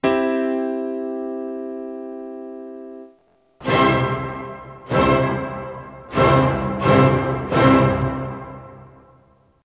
ascolto dell’accordo di Do Maggiore eseguito dal pianoforte e dello stesso accordo eseguito dall’orchestra.
02_Accordo_Do_M_pianoforte_e_orchestra.wma